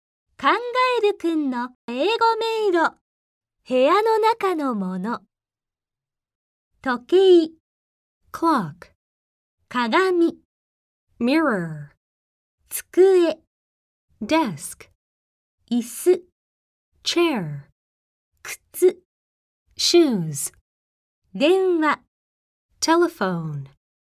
ネイティブスピーカーによる発音でお聞きいただけます。